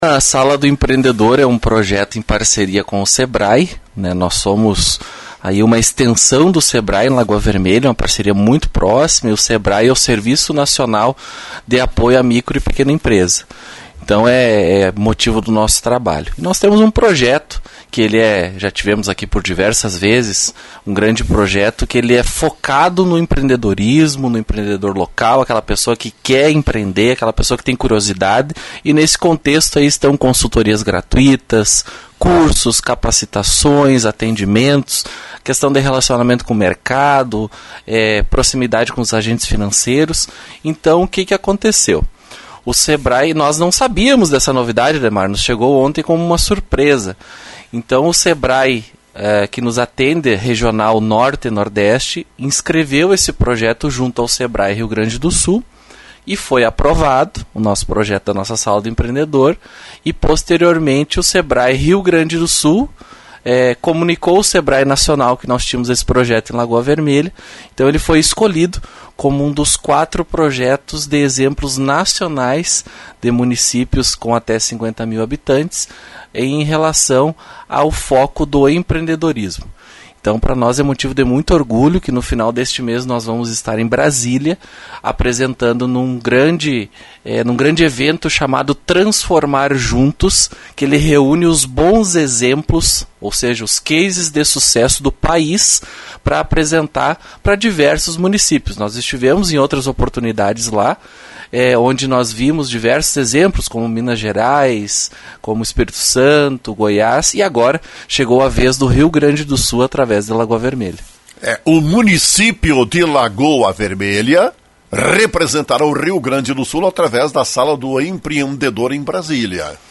Secretário municipal do Desenvolvimento Econômico, Lucas Mota, foi ouvido pela Rádio Lagoa FM.